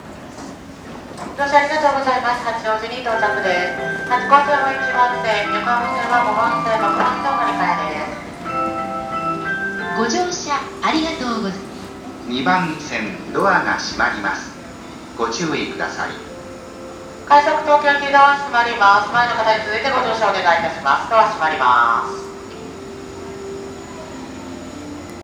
中央線 八王子駅 ２番線 発車メロディー
中央線　八王子駅２番線発車メロディーです。通勤時間帯の録音です。